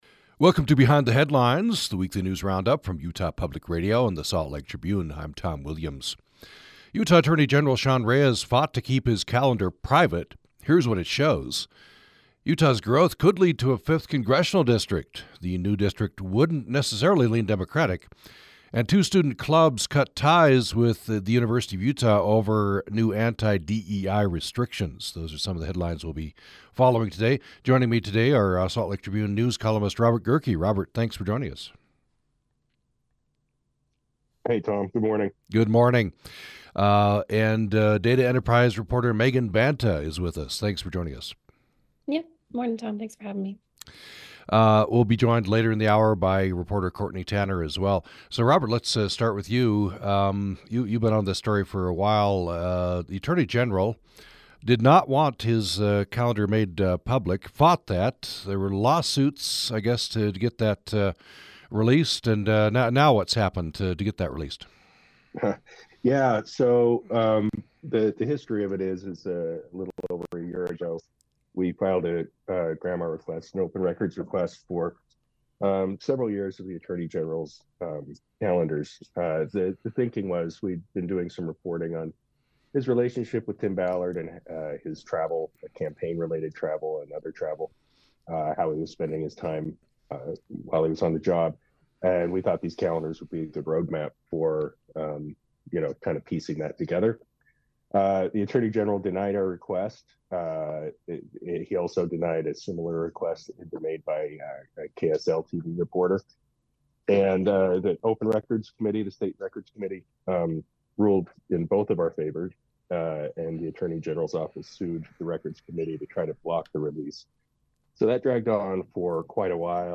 Tribune reporters join us to talk about the latest news, including whether Utah’s growth could lead to a fifth congressional district for the state.